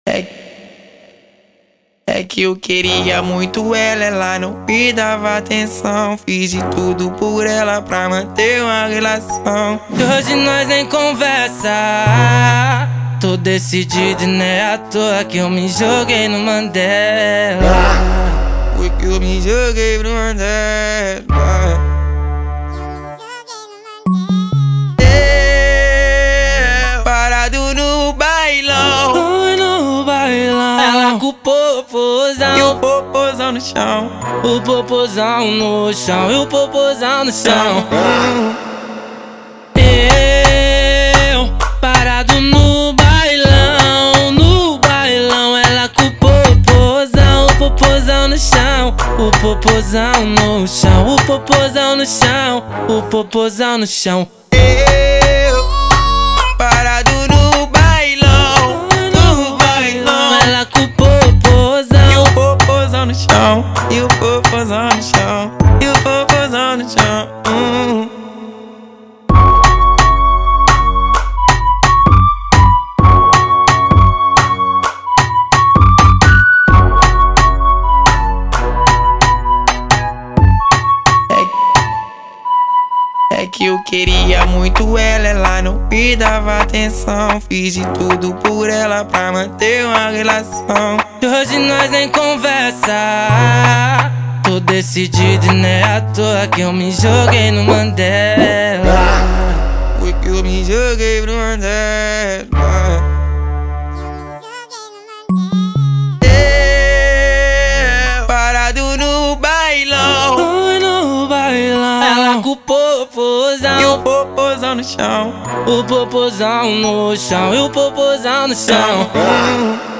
2025-03-14 01:50:16 Gênero: Funk Views